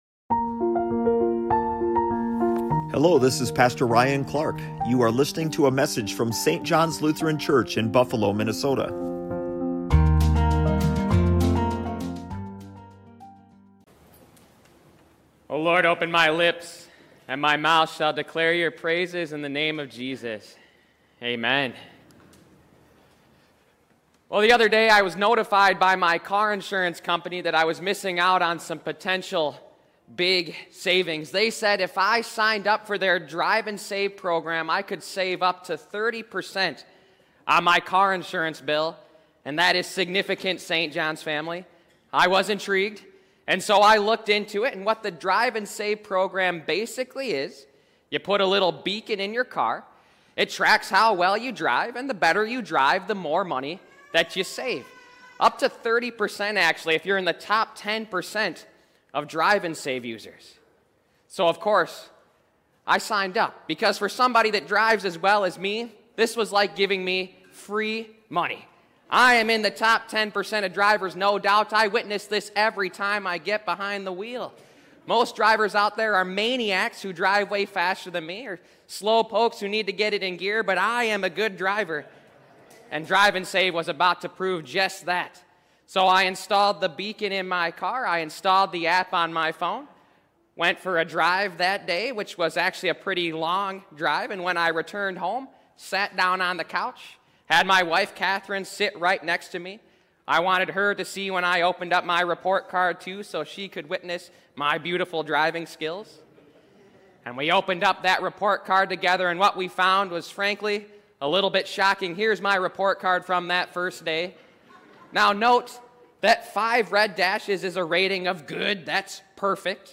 Drive into this sermon and uncover God’s true standard for our lives.